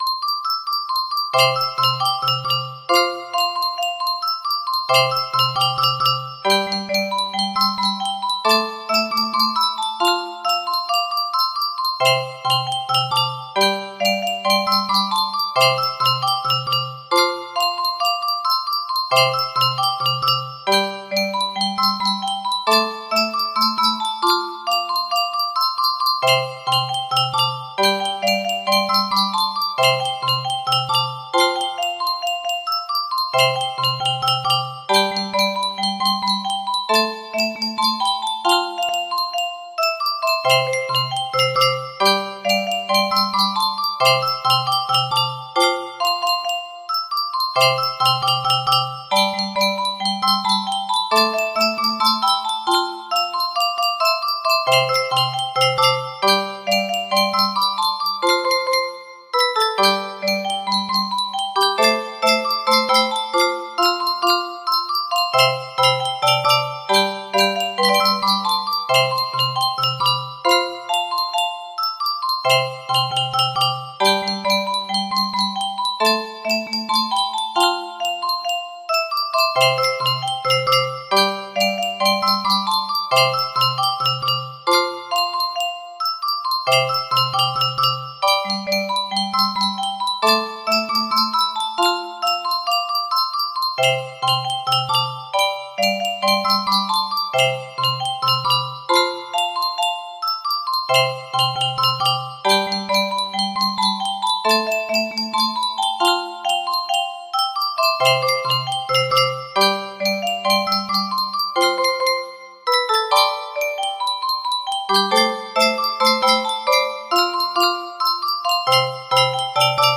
FRIIMINLV music box melody